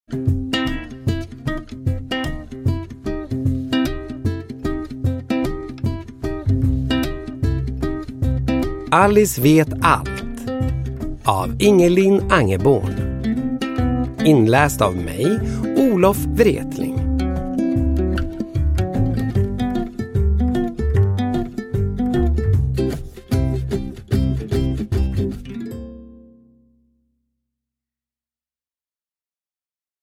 Alice vet allt! – Ljudbok – Laddas ner
Uppläsare: Olof Wretling